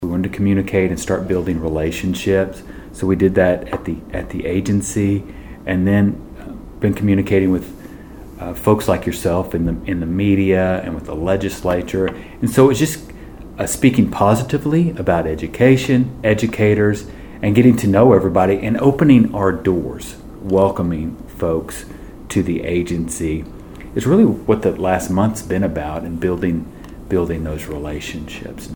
In a sit-down interview with Bartlesville Radio, Fields reflected on his first weeks in office and shared his vision for the future of Oklahoma education.
Lindel Fields on Building Relationships 11-14.mp3